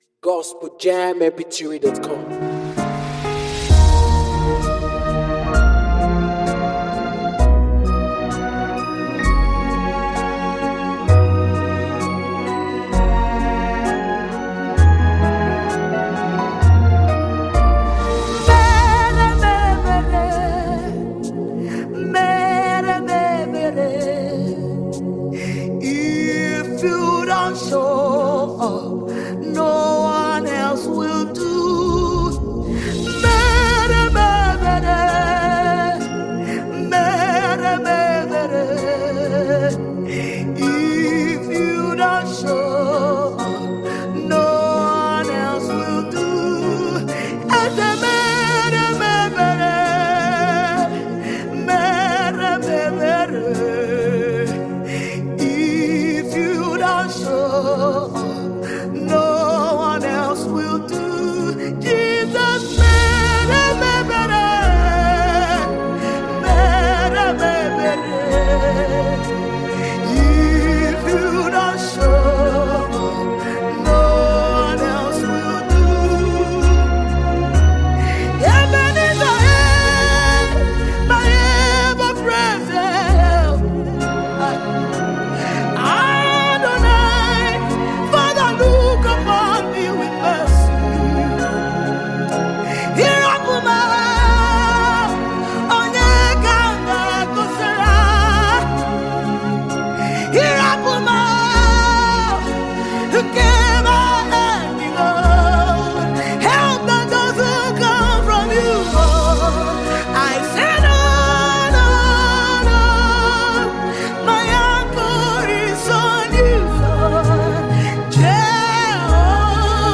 Check out this amazing song by gifted Nigerian musical group